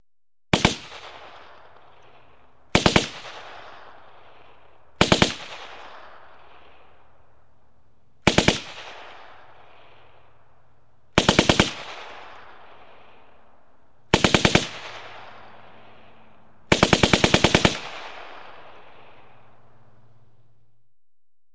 Звук автоматной очереди (Браунинг M1919) (00:05)